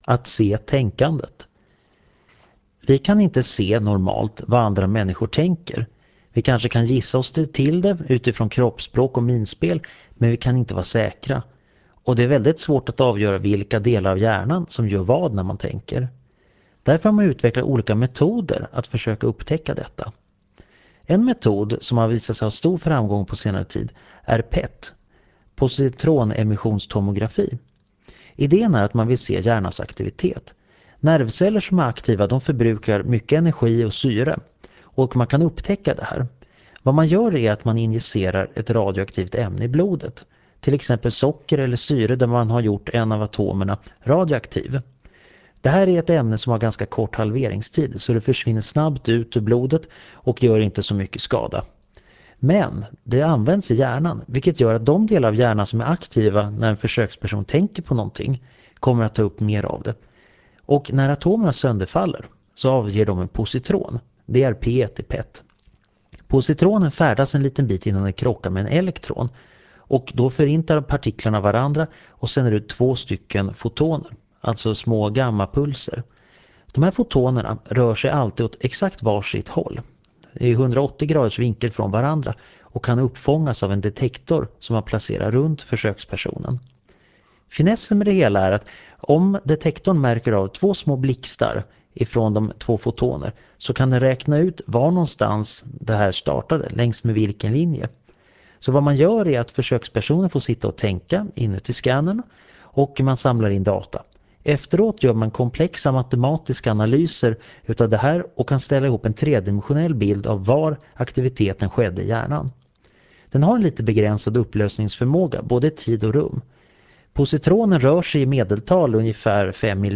Föredraget